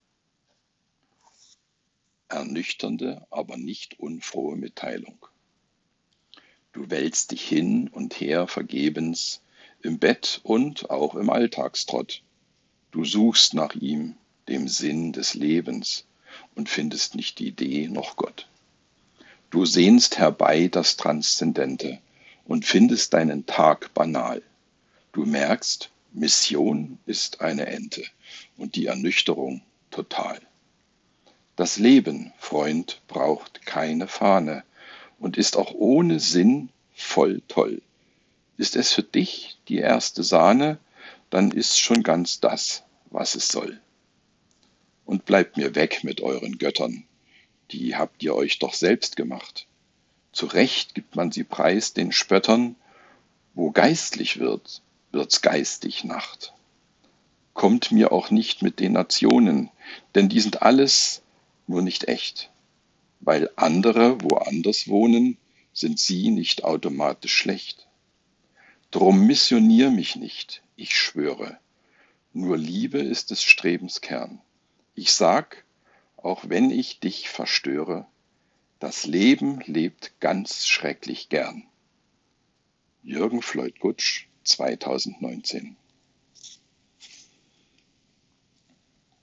📺 Video Lesung